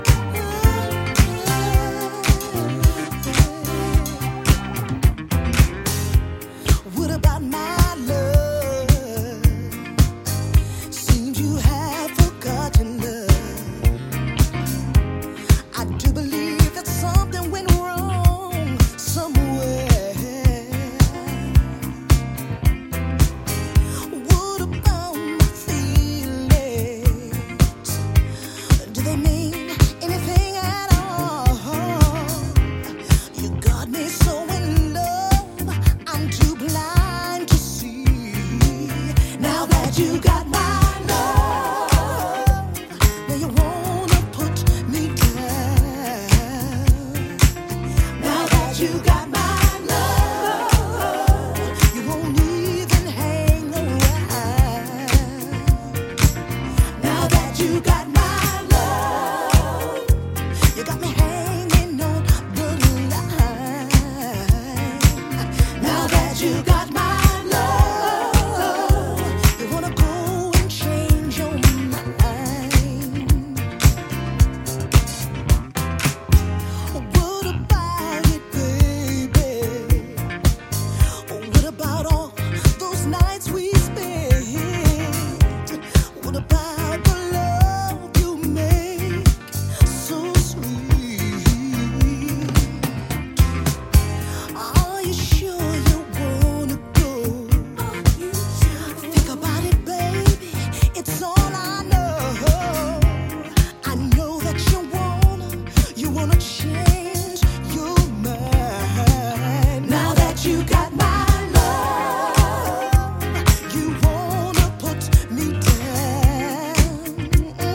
a modern slightly boogiefied take
heartfelt soulful vocal performance
hip-cracking bass guitar and squelchy leads.